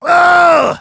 Voice file from Team Fortress 2 German version.
Heavy_painsevere03_de.wav